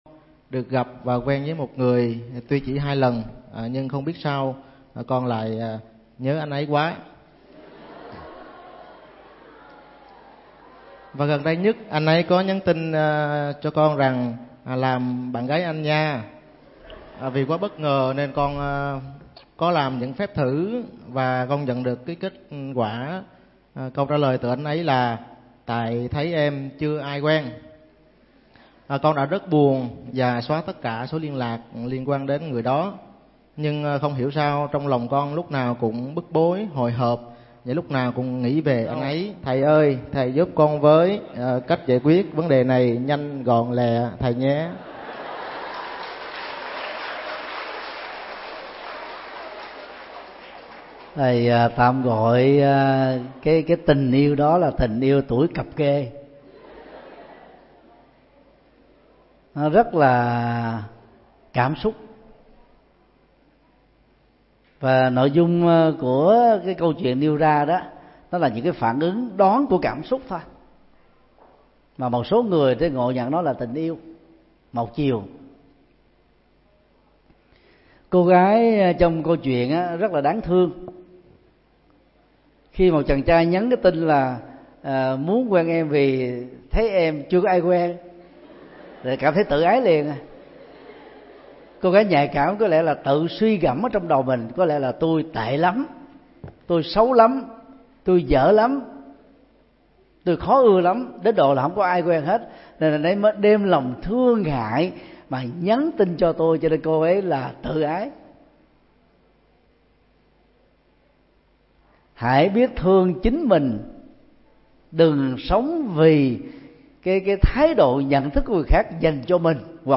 Vấn đáp: Tình yêu tuổi cập kê ( tuổi mới lớn ) – thầy Thích Nhật Từ